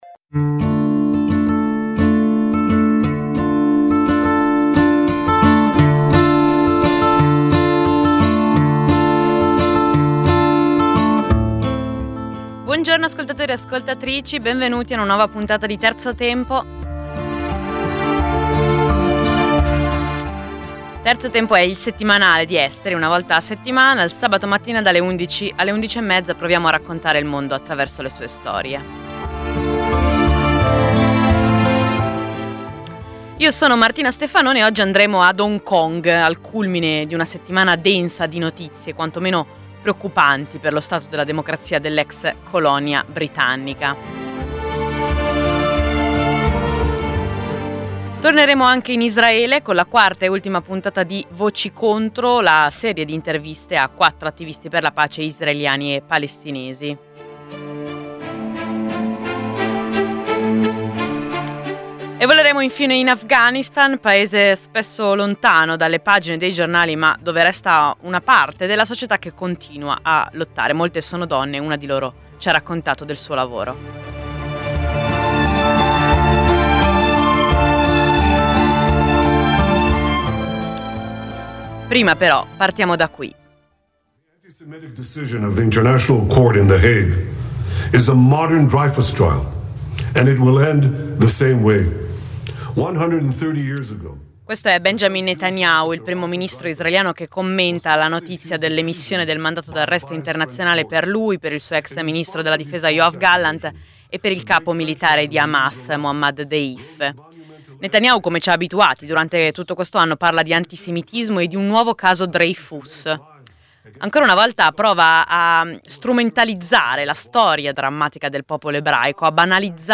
Radio Popolare – Intervista